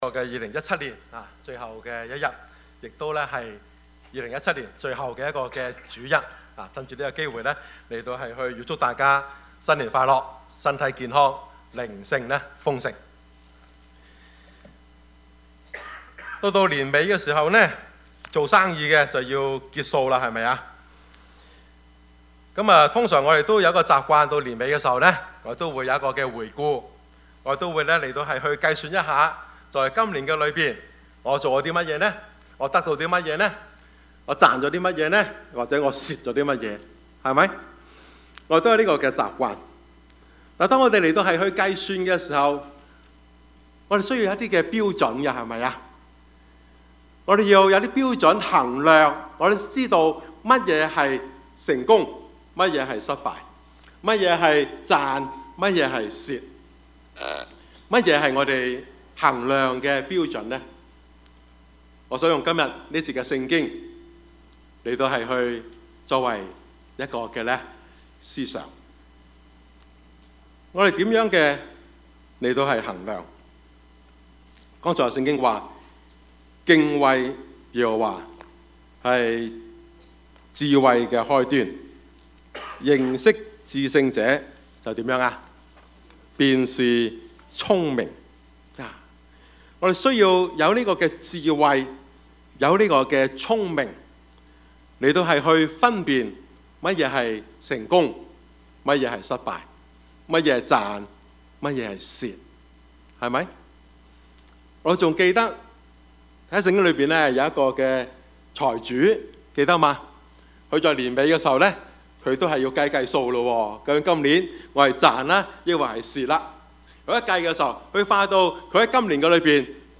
Service Type: 主日崇拜
Topics: 主日證道 « 冷暖人間 陳獨秀(一) »